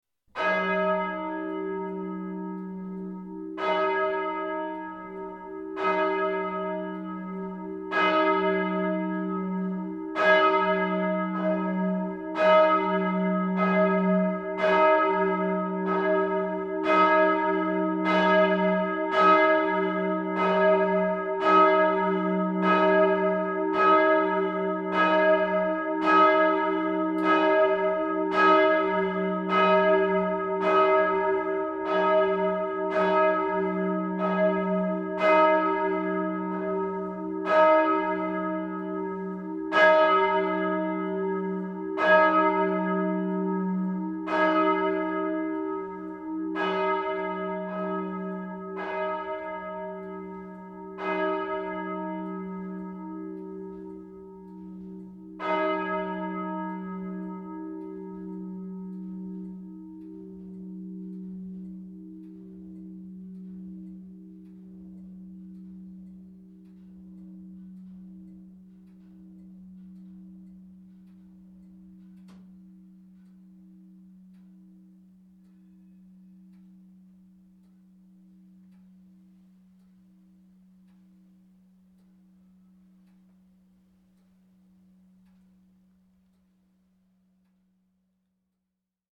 Einige weitere Hörbeispiele zu verschiedenen Glocken:
Lobdeburg-Glocke Würzburg [1.741 KB]
03---wue-lobdeburgglocke-l-ba.mp3